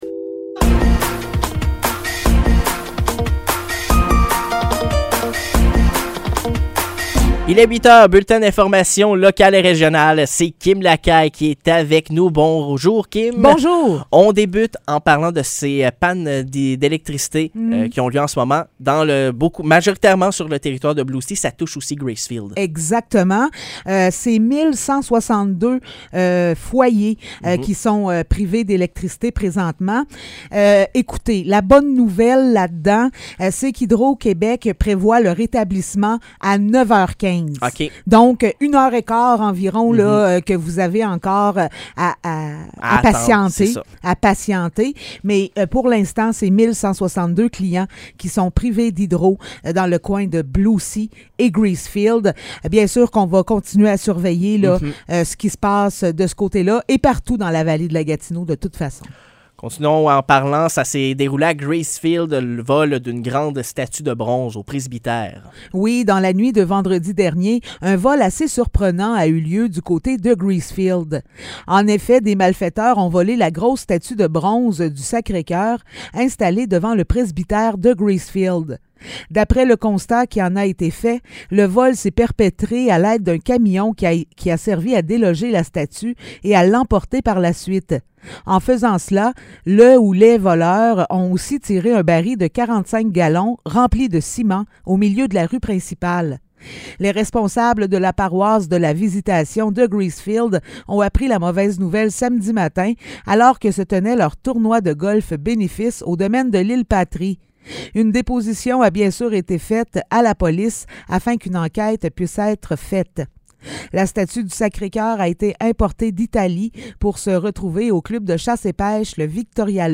Nouvelles locales - 21 juillet 2023 - 8 h